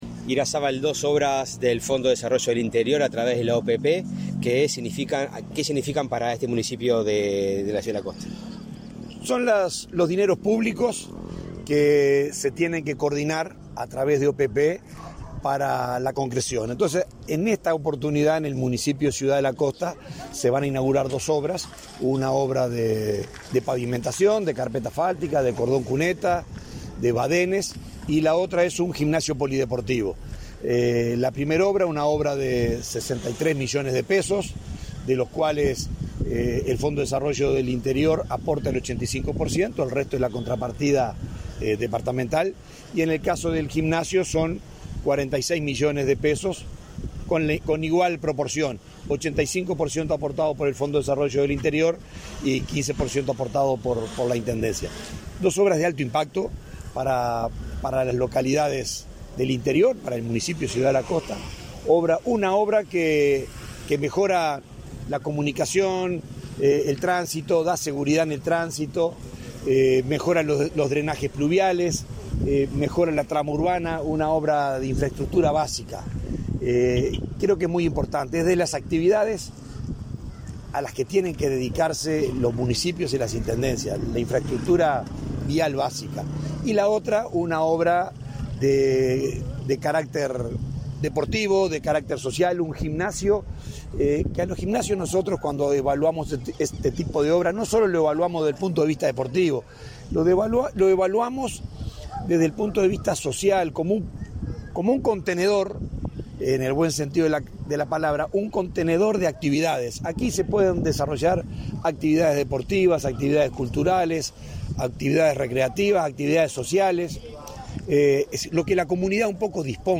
Entrevista al subdirector de la OPP, Benjamín Irazabal